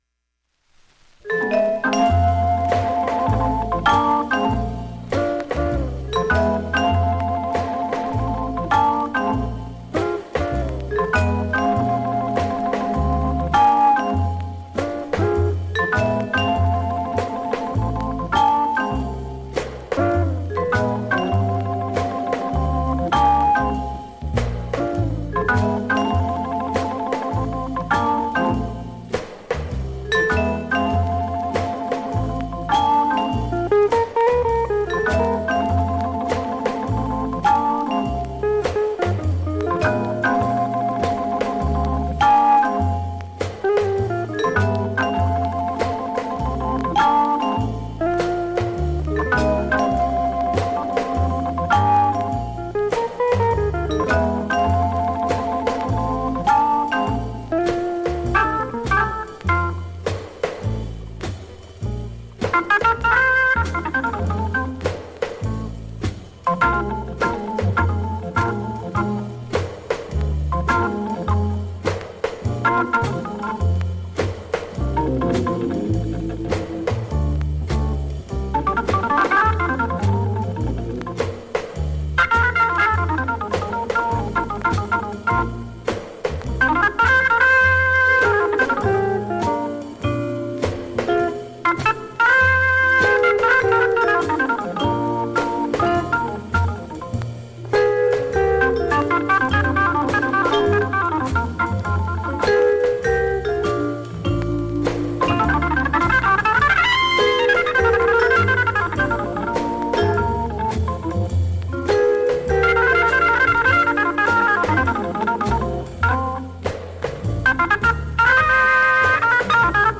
周回プツ出ますがかすかなレベルです。
６回までのかすかなプツが２箇所
３回までのかすかなプツが４箇所
単発のかすかなプツが１１箇所
◆ＵＳＡ盤オリジナル Mono